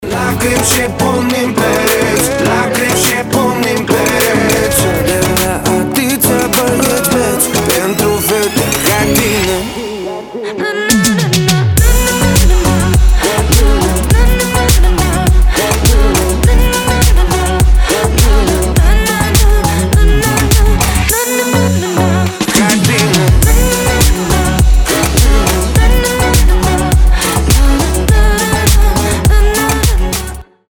• Качество: 320, Stereo
поп
заводные